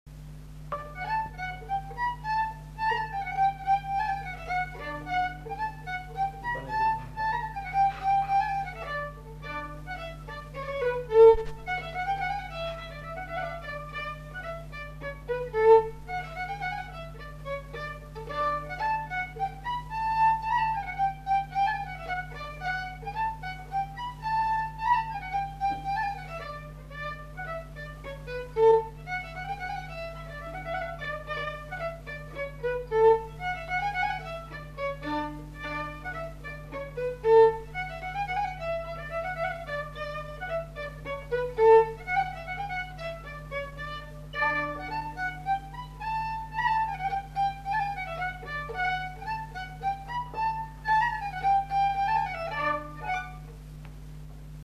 Aire culturelle : Gabardan
Genre : morceau instrumental
Instrument de musique : violon
Danse : rondeau